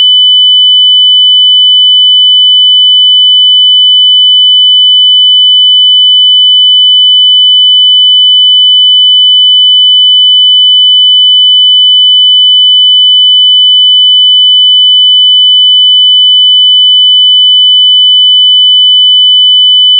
[参考译文] TPA2011D1：TPA2011D1输出噪声高和波形失真
如果没有信号输入、我们也可以听到扬声器发出的噪音。
3.1KHzl  输入至 TPA 2011D1、在 RC 电路后测试输出端口